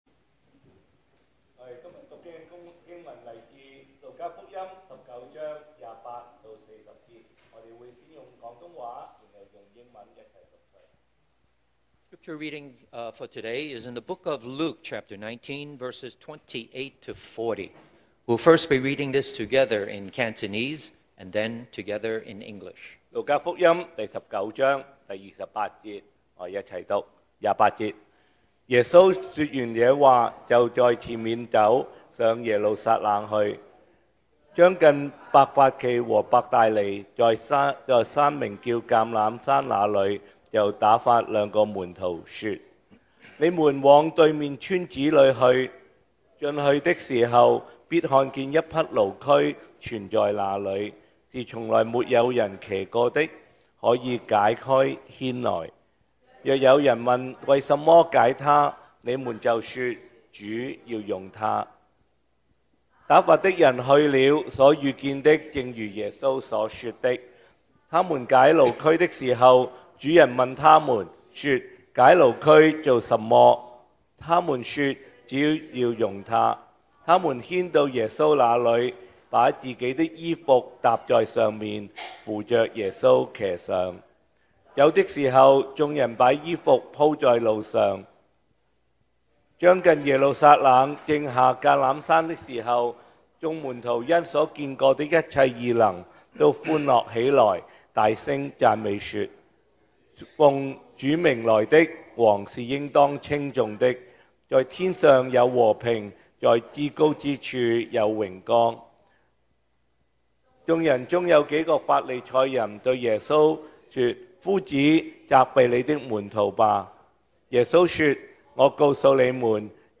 2025 sermon audios
Passage: Luke 19:28 - 40 Service Type: Sunday Morning